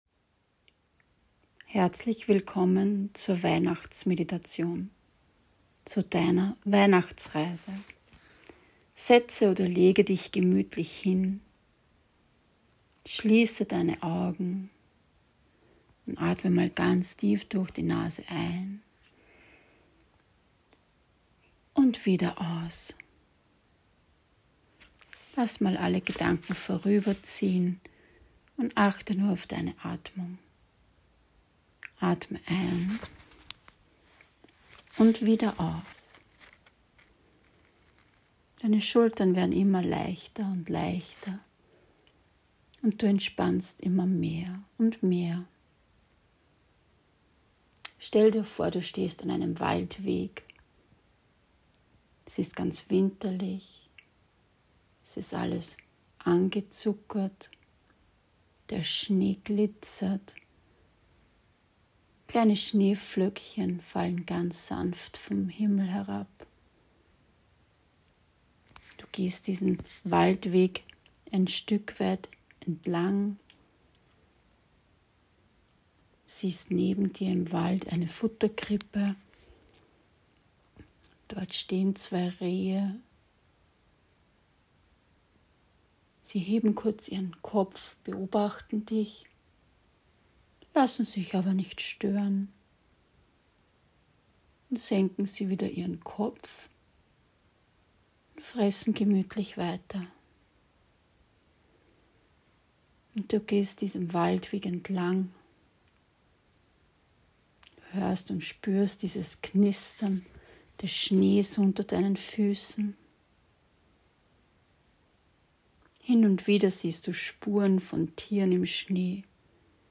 Geführte Meditationen – Gut für Körper, Geist und Seele